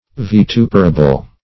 Search Result for " vituperable" : The Collaborative International Dictionary of English v.0.48: Vituperable \Vi*tu"per*a*ble\, a. [L. vituperabilis: cf. F. vitup['e]rable.] Liable to, or deserving, vituperation, or severe censure.